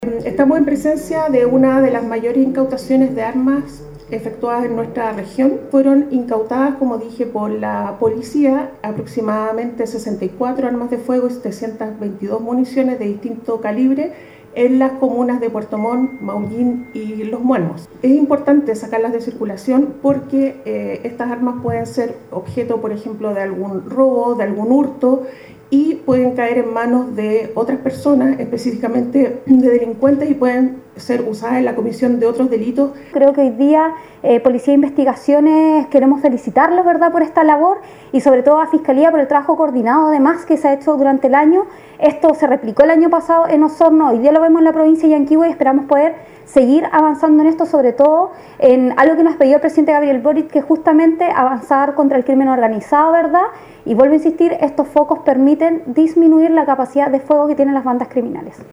Cabe destacar que esta exitosa diligencia corresponde a una indagación por infracción a la Ley 17.798 sobre control de armas y explosivos, la cual tiene por objetivo sacar de circulación aquellas armas que estén de manera irregular en la zona, como lo detallaron la fiscal regional, Carmen Gloria Wittwer y Giovanna Moreira, delegada región de Los Lagos.